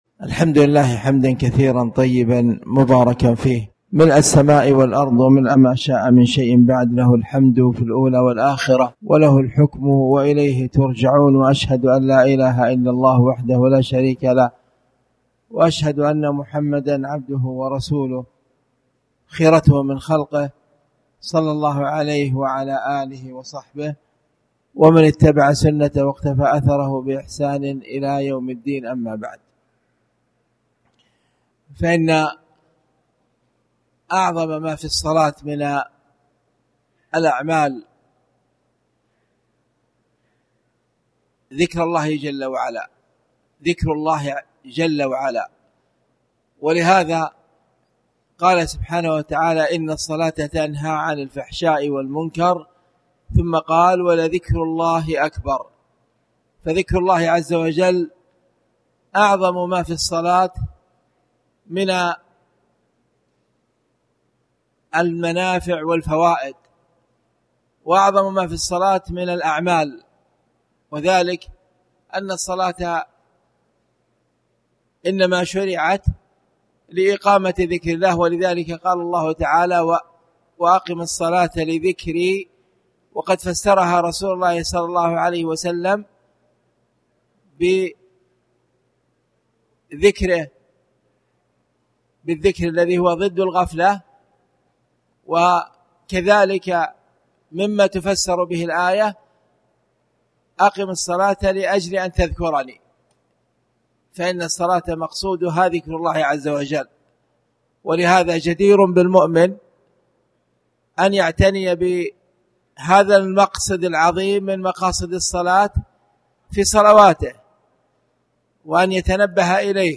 تاريخ النشر ١٧ محرم ١٤٣٩ هـ المكان: المسجد الحرام الشيخ
17mhrm-ktab-alslah-bad-alfjr.mp3